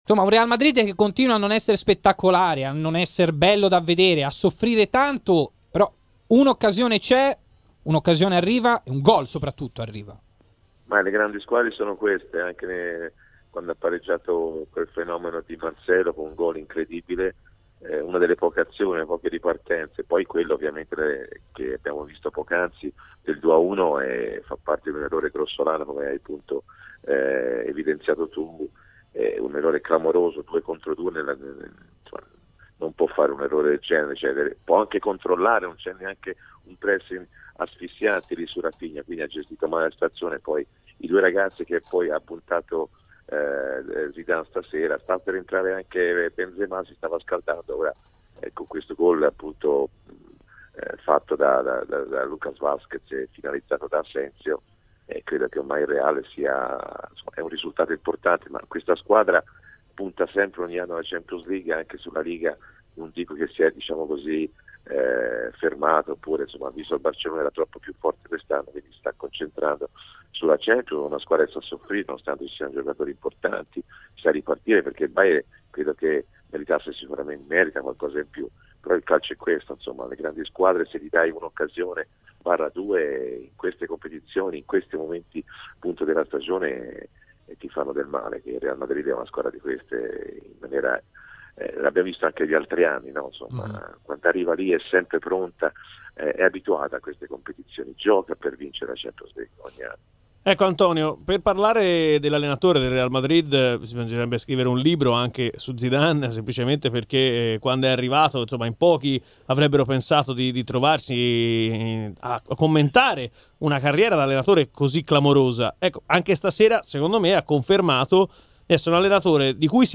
Antonio Di Gennaro, opinionista RMC Sport, al commento del secondo tempo di Bayern Monaco-Real Madrid e su Klopp.